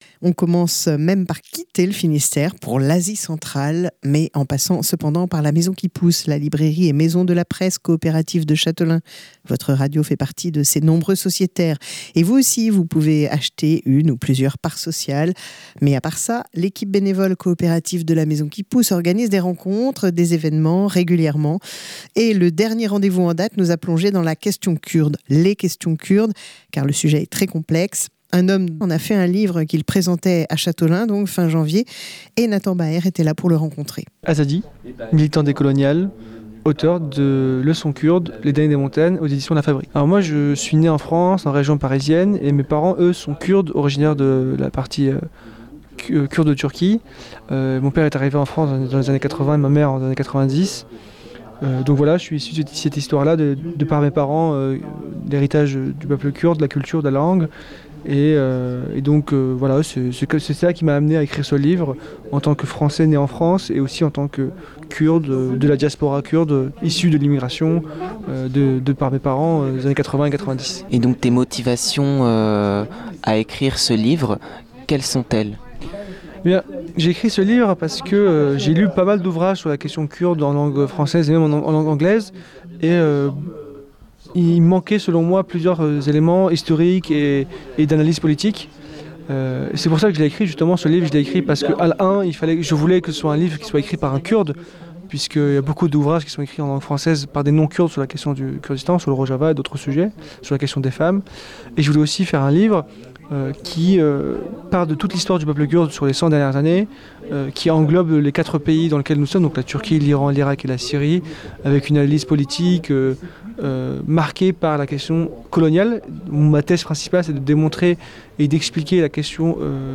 à La Maison qui pousse à Châteaulin.